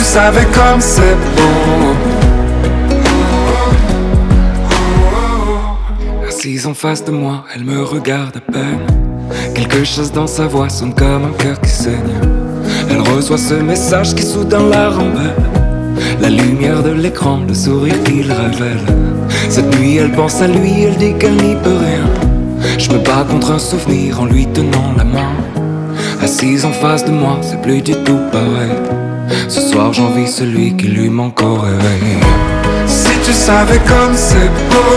# Французская поп-музыка